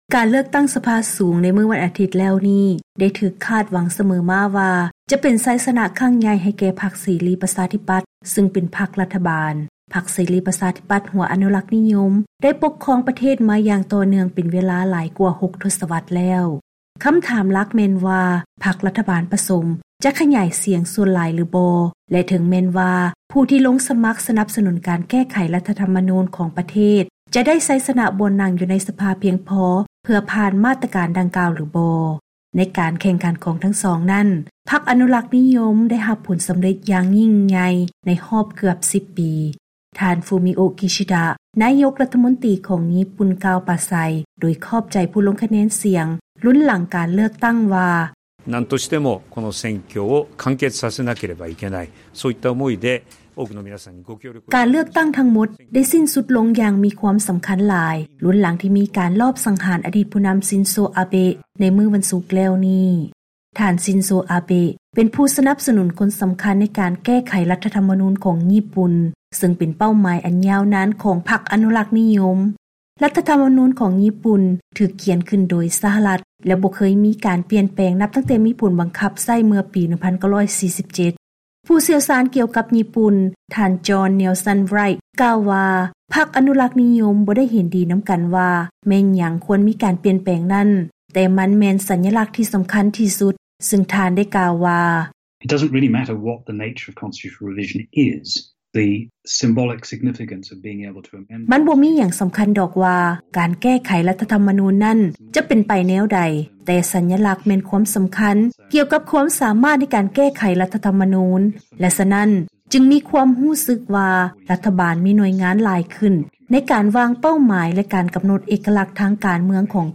ເຊີນຮັບຟັງລາຍງານກ່ຽວກັບ ຄວາມຕ້ອງການໃນການແກ້ໄຂລັດຖະທໍາມະນູນຂອງພັກອະນຸລັກນິຍົມຍີ່ປຸ່ນ